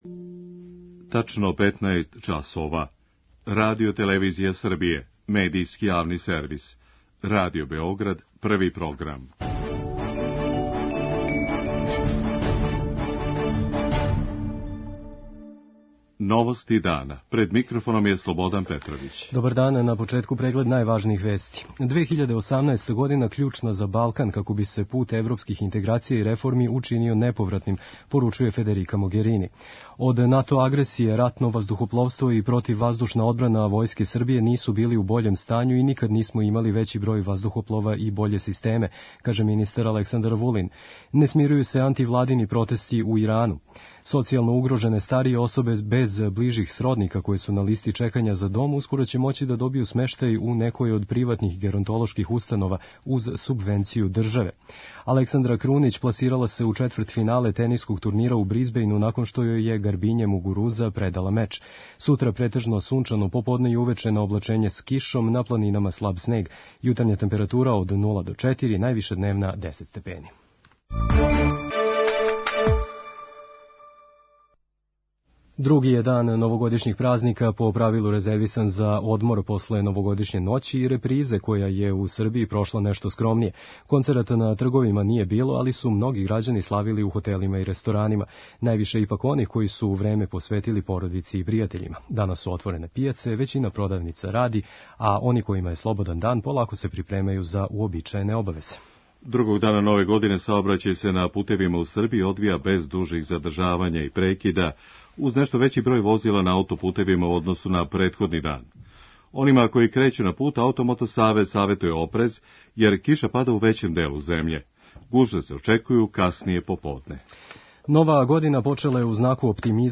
Тим поводом, у Новостима дана са аналитичарима разговарамо о перспективама Србије на путу ка чланству у Европској унији.